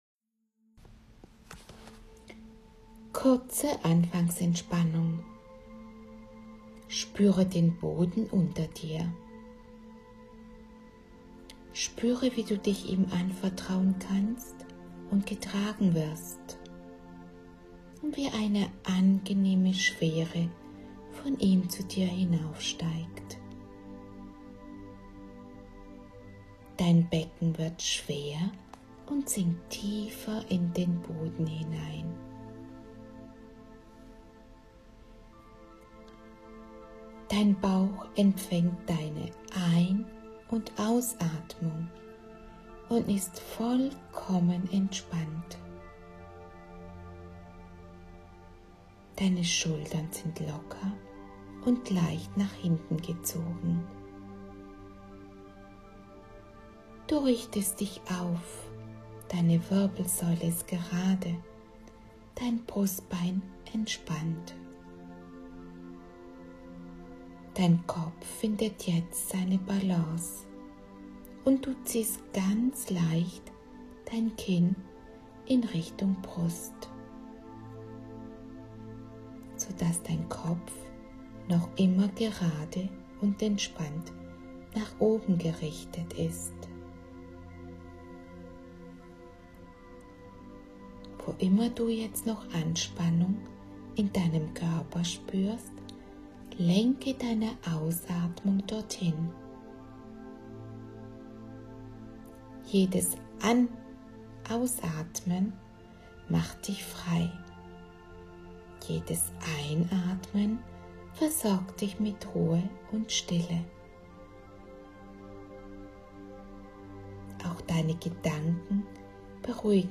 Durch Meditationen im Alltag mehr innere Ruhe und Ausgeglichenheit erreichen. Höre dir meine geführten Meditationen an und komme ganz bei dir an.
anfangsmeditation-02.mp3